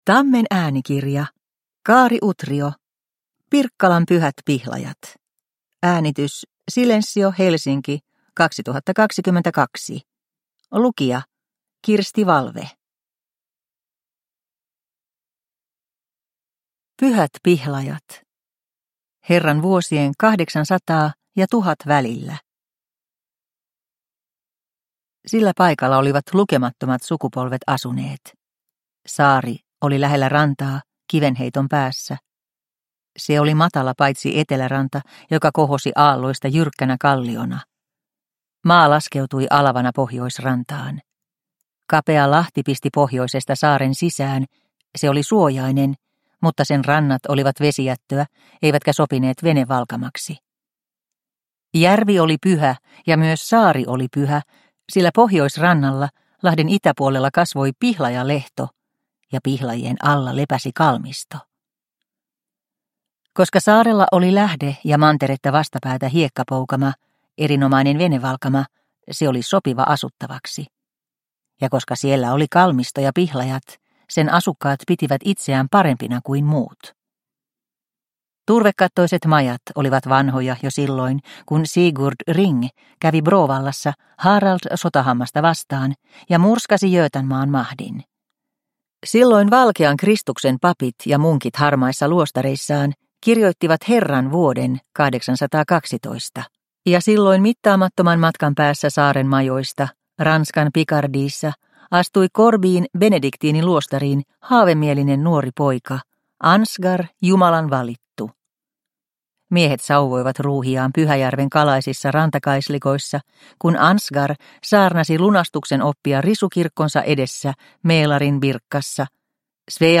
Pirkkalan pyhät pihlajat – Ljudbok – Laddas ner